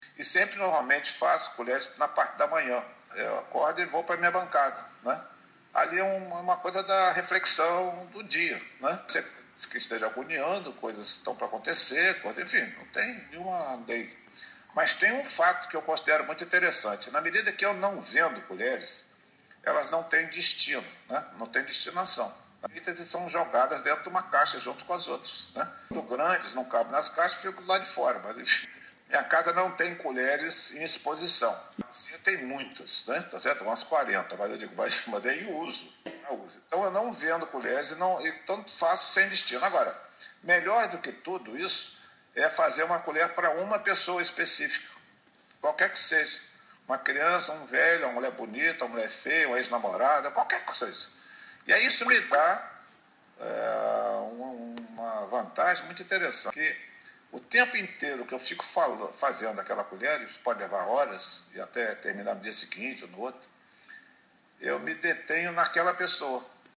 entrevista por Skype